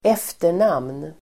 Uttal: [²'ef:ter_nam:n]